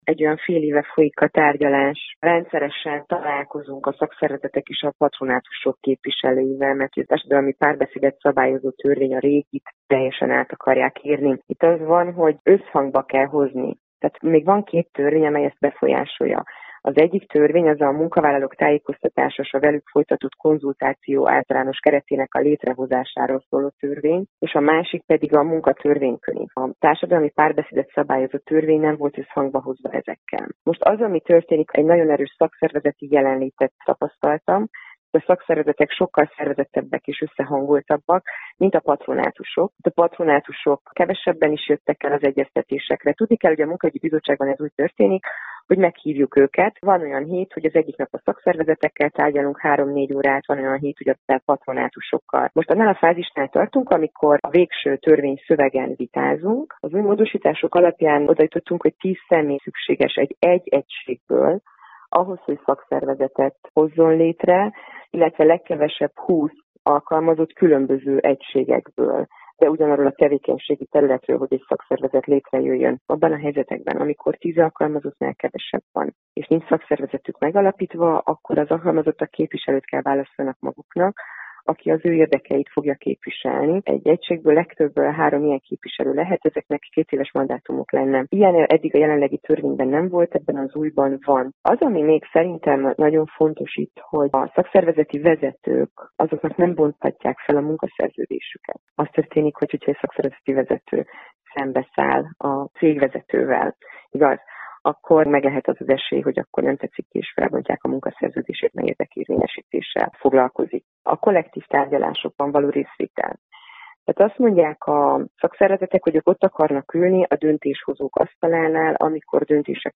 A tervezett módosításokból Csép Andrea képviselő nyilatkozott.